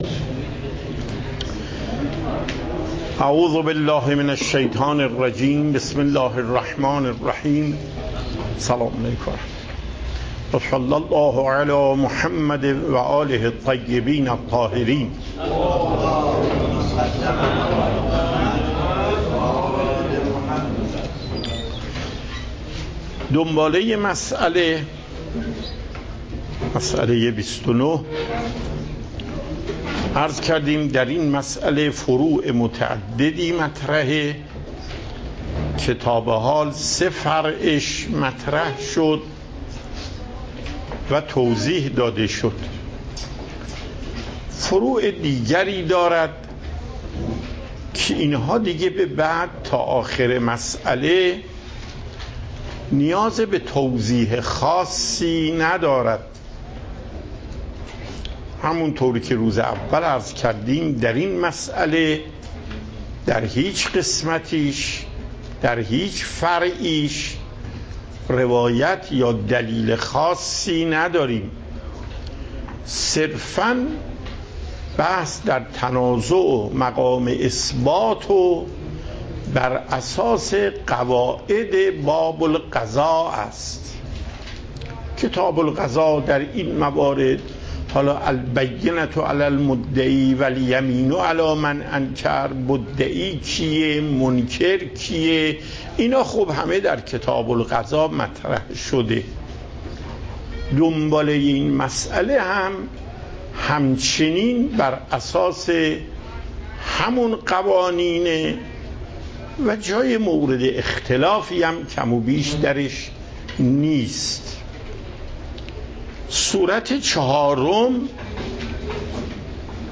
صوت درس